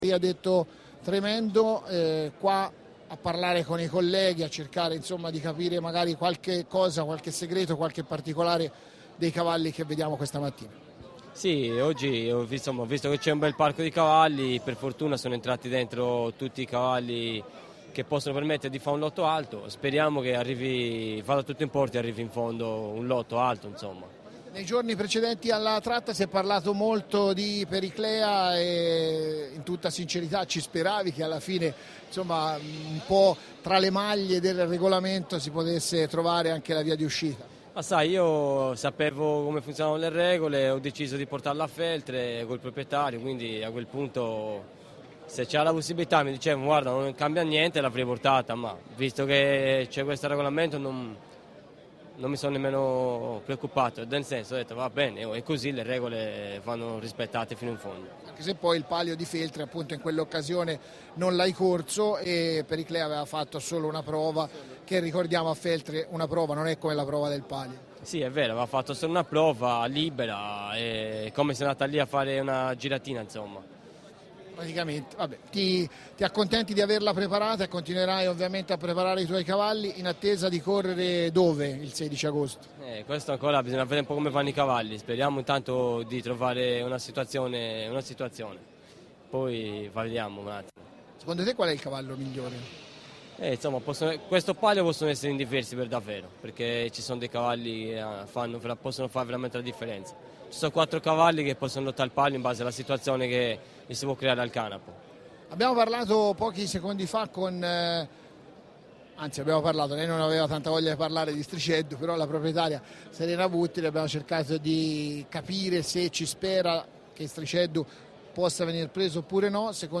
ASCOLTA L’ULTIMO NOTIZIARIO